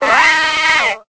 Cri de Tiboudet dans Pokémon Épée et Bouclier.